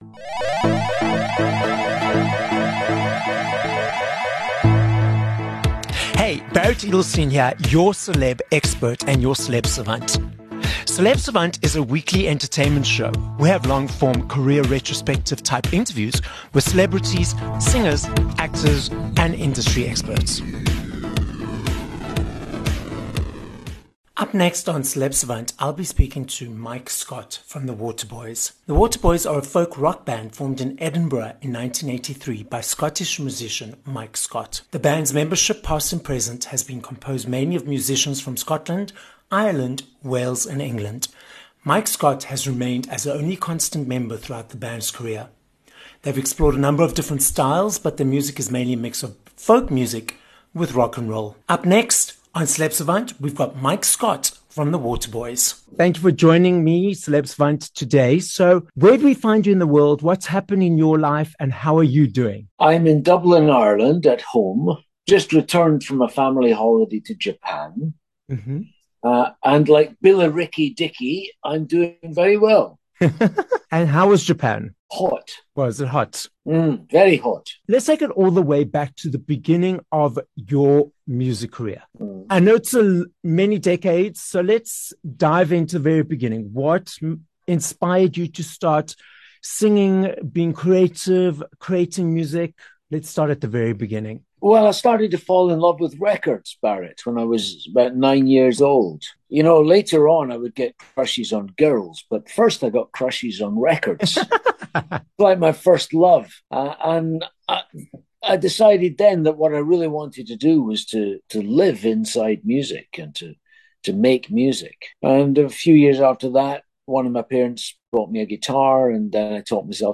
21 Nov Interview with Mike Scott (The Waterboys)